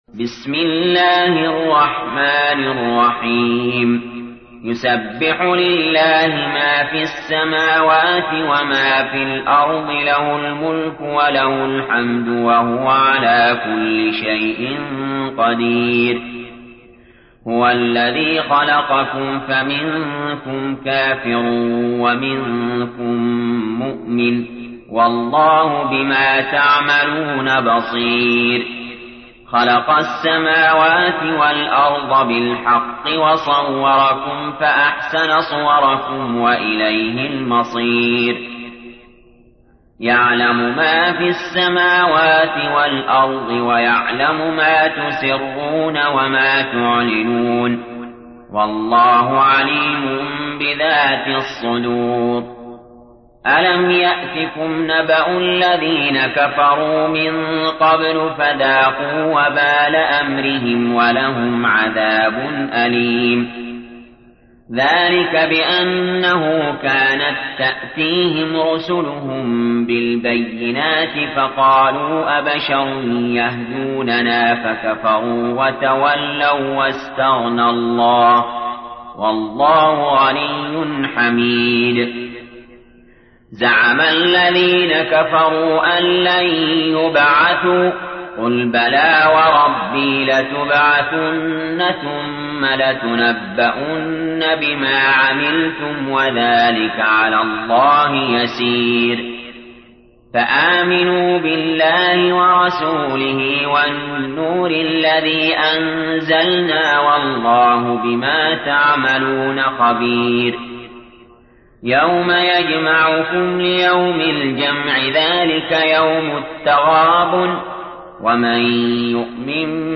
تحميل : 64. سورة التغابن / القارئ علي جابر / القرآن الكريم / موقع يا حسين